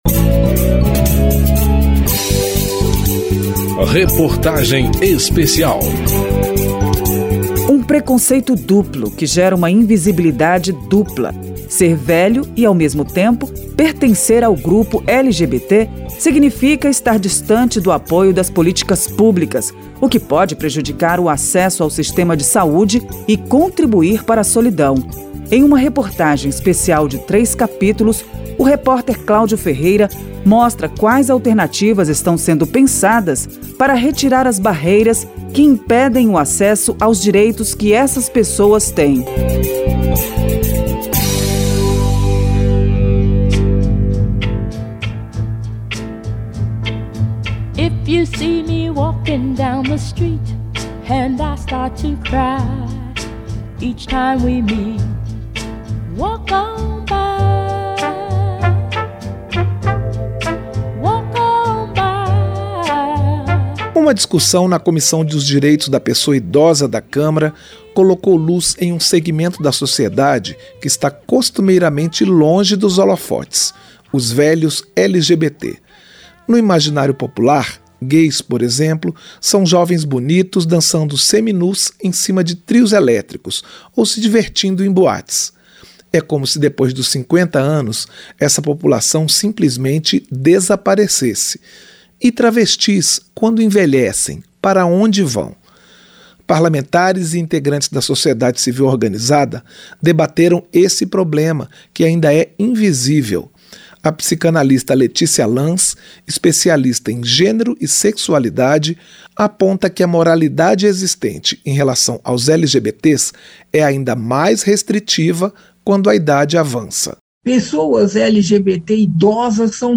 Reportagem Especial
E o deputado Eduardo Barbosa (PSDB-MG).